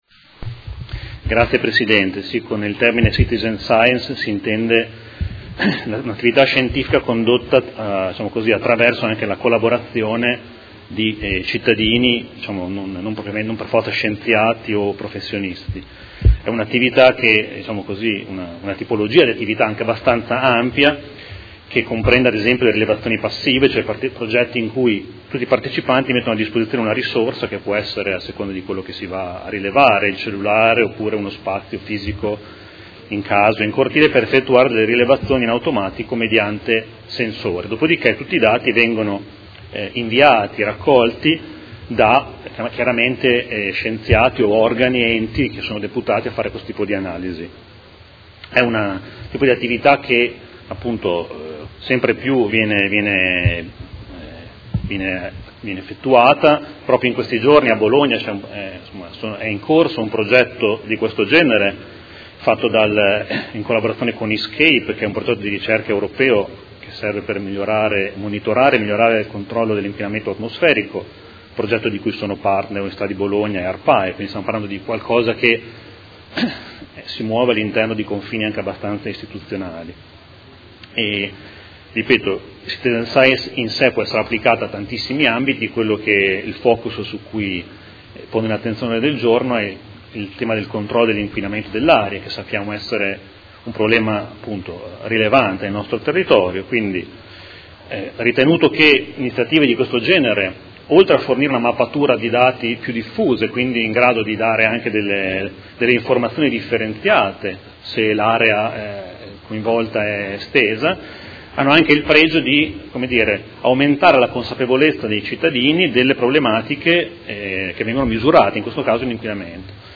Mario Bussetti — Sito Audio Consiglio Comunale
Seduta del 20/12/2018. Presenta Ordine del Giorno Prot. Gen. 207659